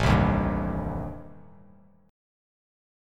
Am7b5 chord